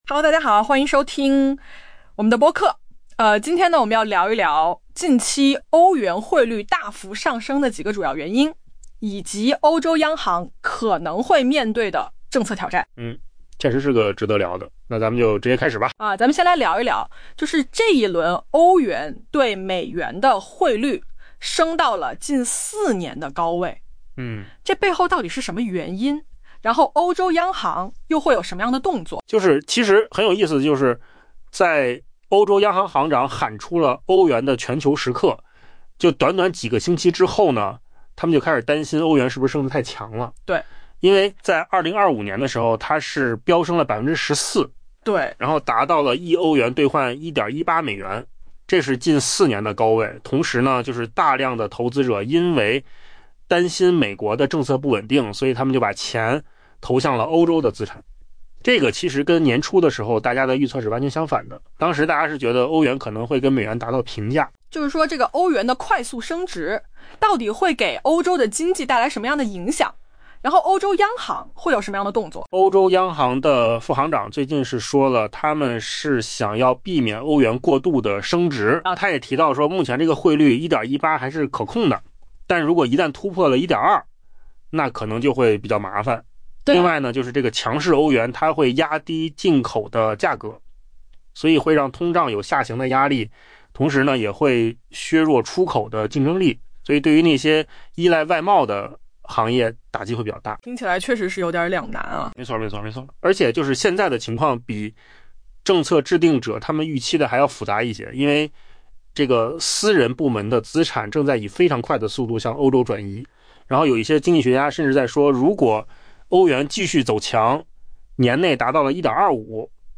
AI播客：换个方式听播客 下载mp3
音频由扣子空间生成